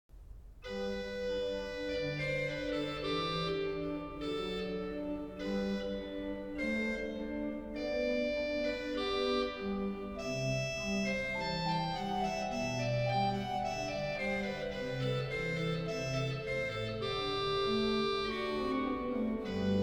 Cromorne